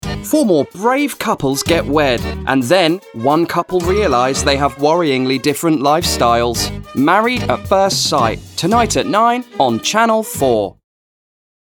• Male
Bright, Clear
Continuity.mp3